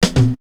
Break 29.wav